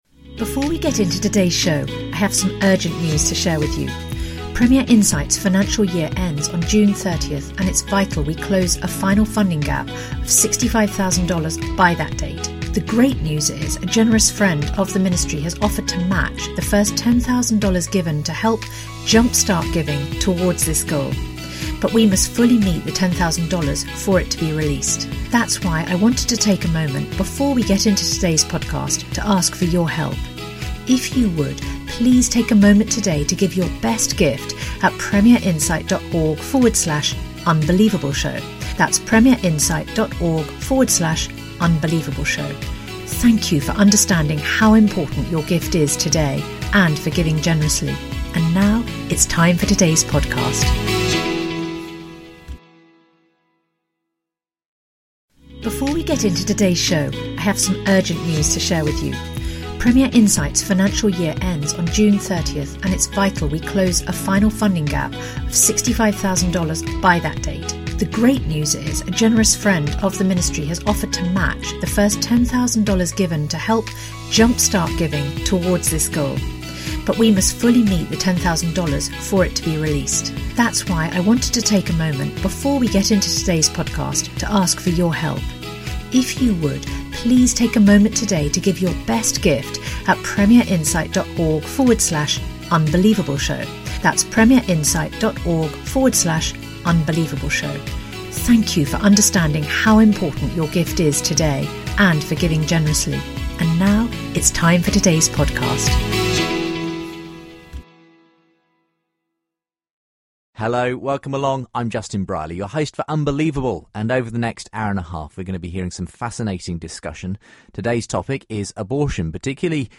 debate abortion and disability